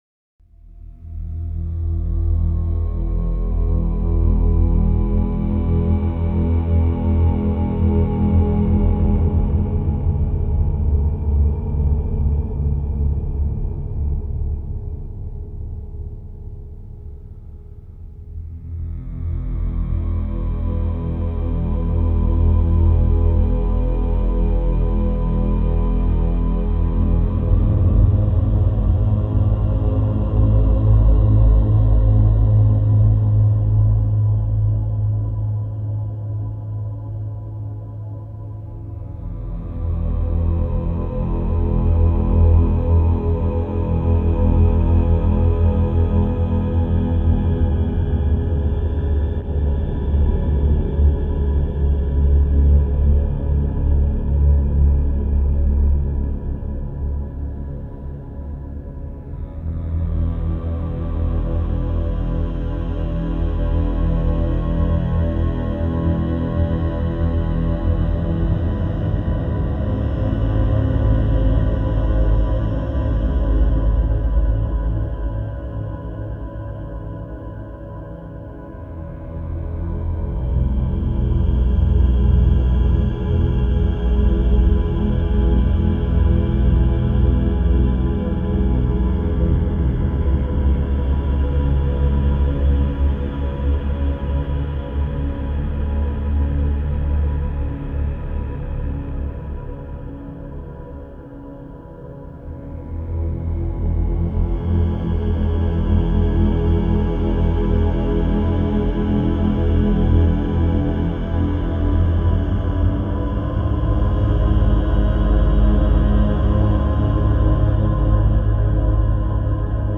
*фоновая композиция –